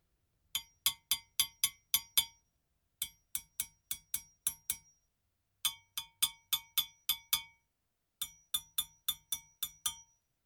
ギニア製 ドゥンドゥンベル
リング/スティック~裏リング/スティック